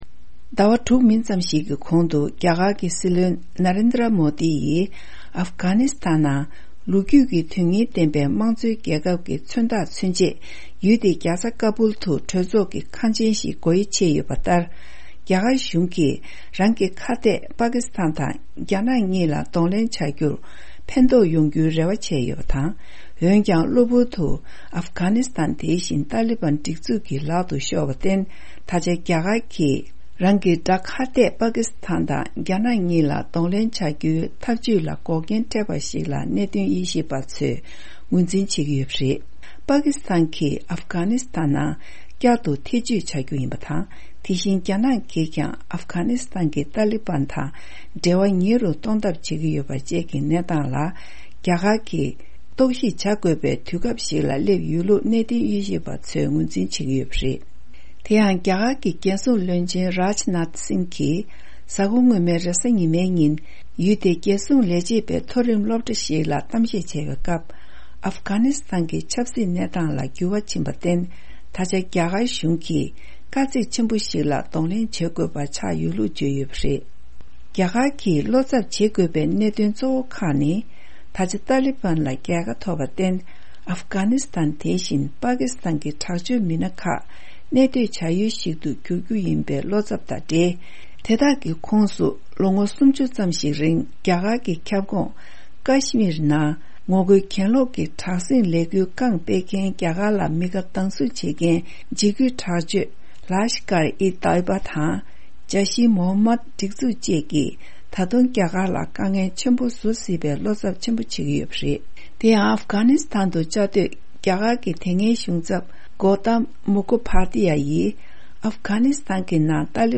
༄༅།། ཉེ་ཆར་ཏ་ལི་བྷན་ཤོག་ཁག་གིས་གློ་བུར་དུ་ཨབ་གྷ་ཎི་སི་ཐན་ལ་དབང་སྒྱུར་བྱས་ནས་རྒྱ་གར་གྱིས་པ་ཀི་སི་ཐན་དང་རྒྱ་ནག་གཉིས་ལ་གདོང་ལེན་བྱ་རྒྱུའི་འཐབ་ཇུས་ལ་འགོག་རྐྱེན་འཕྲད་རྒྱུ་ཡིན་པ་བཅས་ཀྱི་སྐོར། འདི་ག་ཨ་རིའི་རླུང་འཕྲིན་ཁང་གི་གསར་འགོད་པས་སྤེལ་བའི་གནས་ཚུལ་ཞིག་